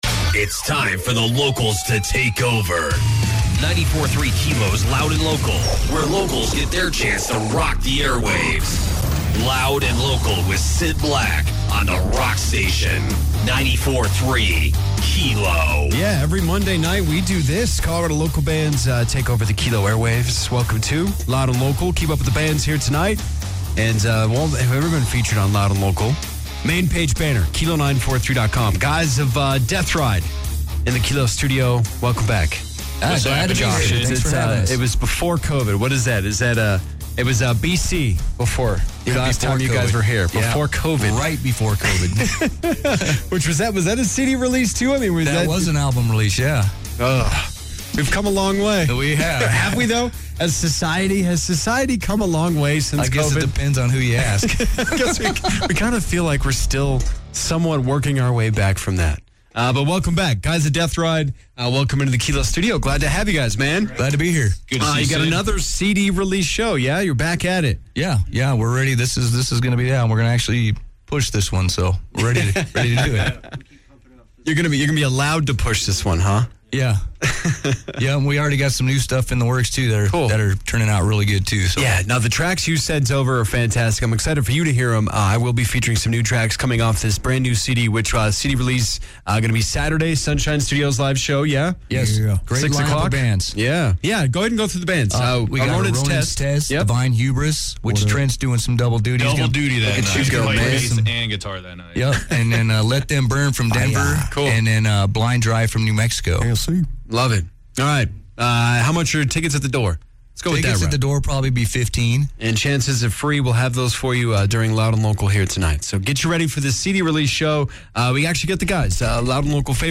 Loud & Local Rewind 5/12/25 featuring; A Ronin’s Test and an in studio interview with Deathride.